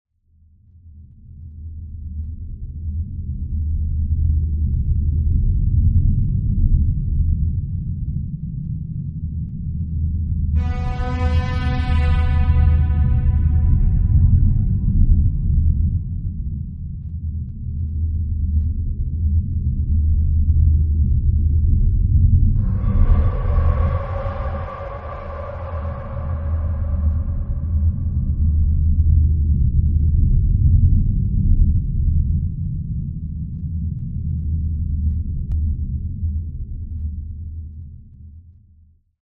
ambient.mp3.svn-base